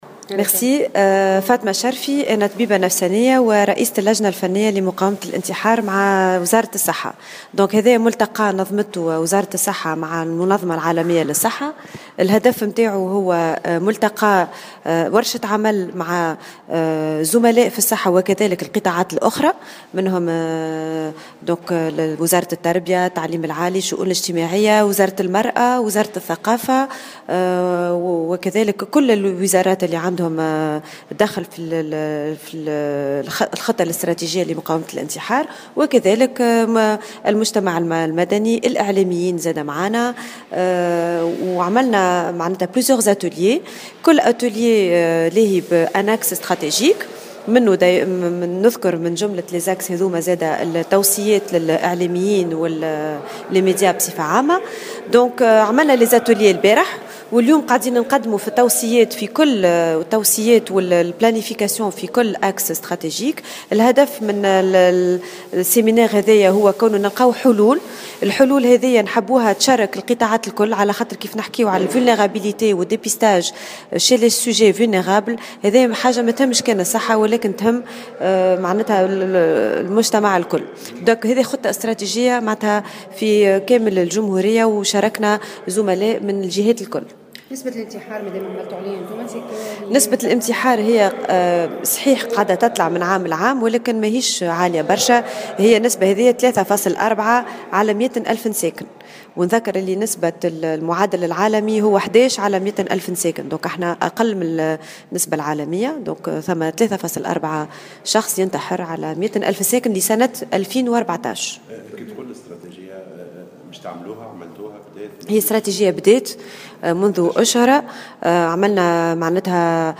في تصريح للجوهرة أف أم اليوم السبت بالحمامات